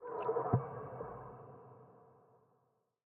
Minecraft Version Minecraft Version snapshot Latest Release | Latest Snapshot snapshot / assets / minecraft / sounds / block / dried_ghast / ambient_water1.ogg Compare With Compare With Latest Release | Latest Snapshot
ambient_water1.ogg